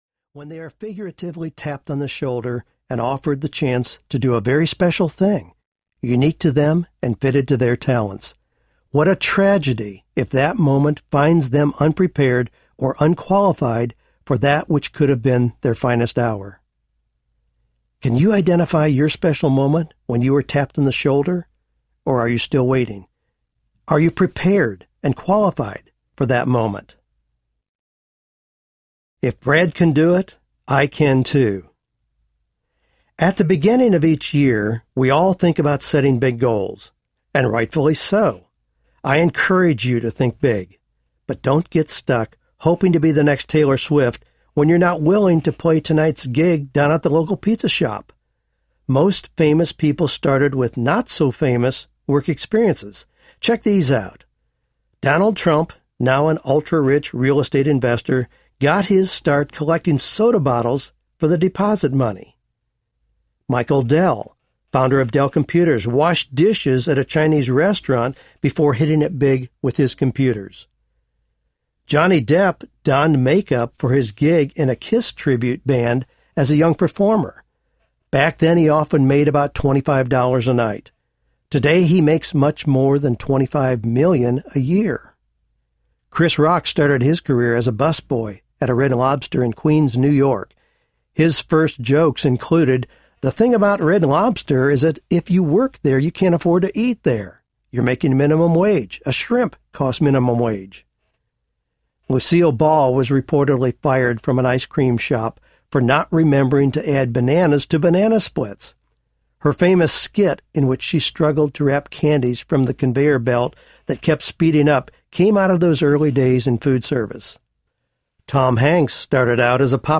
Wisdom Meets Passion Audiobook
6.2 Hrs. – Unabridged